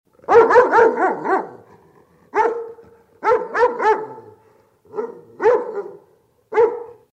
Рингтоны » звуки животных » Собака Лает